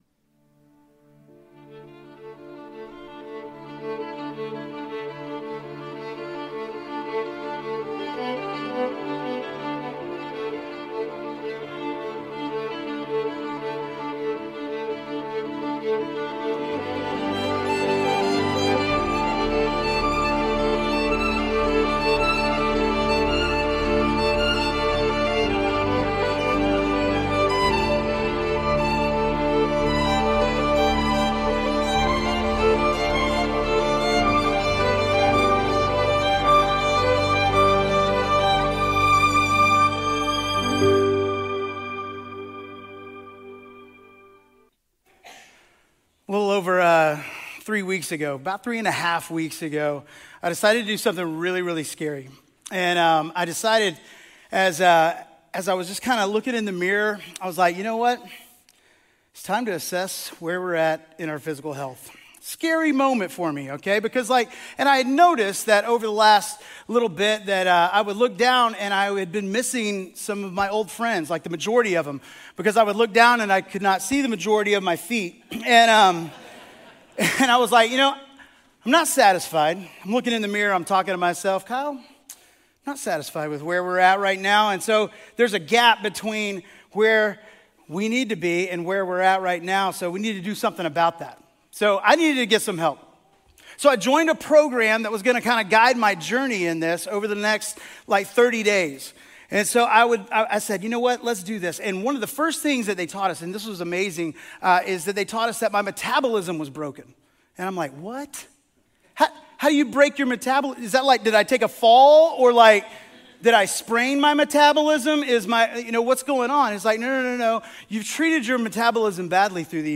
For the next seven weeks, we’ll explore these questions and learn what it means to have life in Christ in our new sermon series, Abundant.